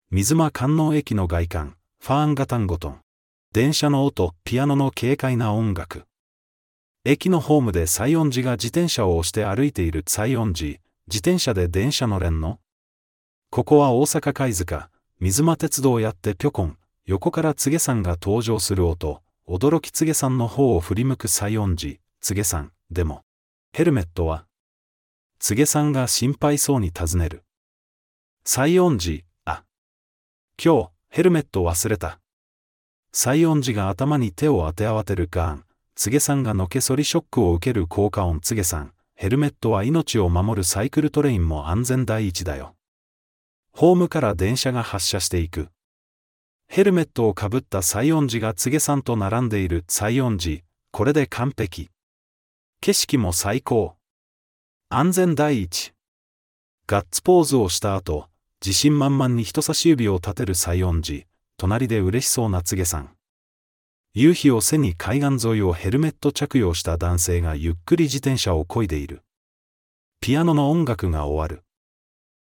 音声ガイド(音声ファイル:835.5KB)